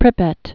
(prĭpĕt) or Pri·pyat (-yət) or Pry·pyat' (-yəch)